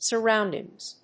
/sɝˈaʊndɪŋz(米国英語), sɜ:ˈaʊndɪŋz(英国英語)/